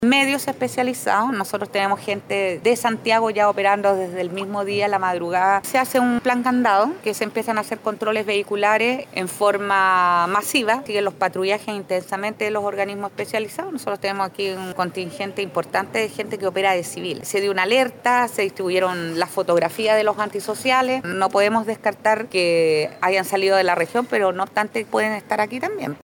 Por último, Patricia Vásquez, general de Zona de Carabineros, dio cuenta sobre las estrategias de búsqueda que se están llevando a cabo, con despliegues en rutas principales y de efectivos de civil, para encontrar a los reos.
cu-crisis-carcel-valpo-carabineros.mp3